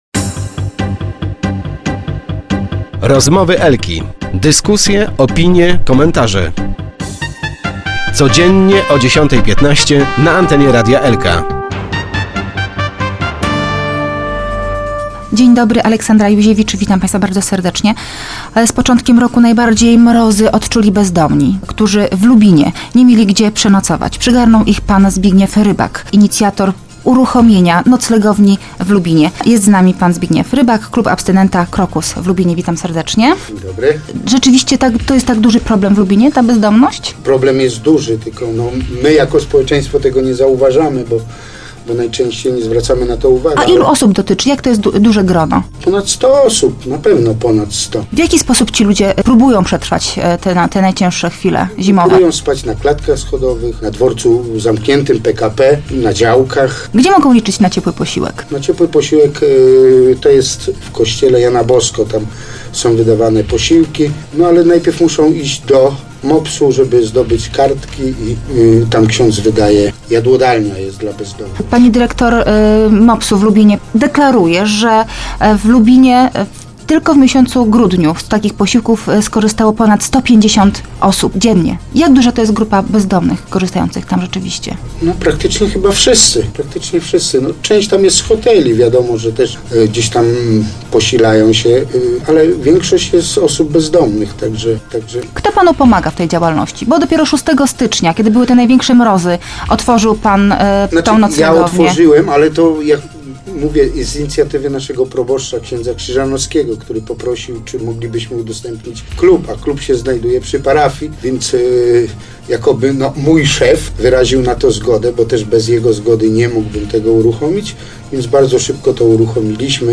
Start arrow Rozmowy Elki arrow Noclegowni brakuje opału